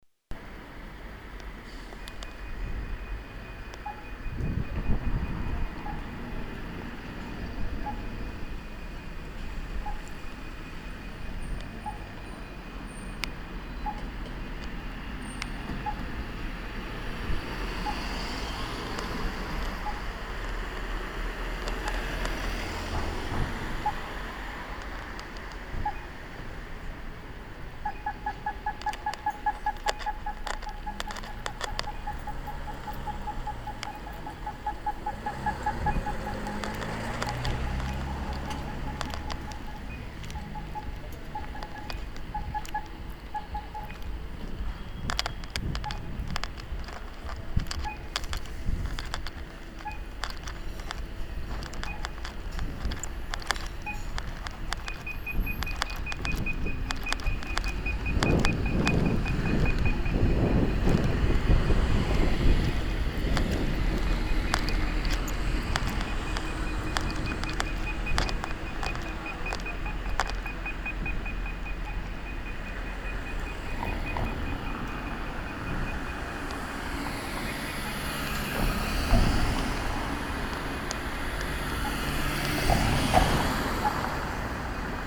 ATAS – Akustisch taktile Signalanlagen:
Hörbeispiele: nach Aktivierung durch Funkhandsender:
Hörbeispiel Kreuzung Herz Jesu Kirche: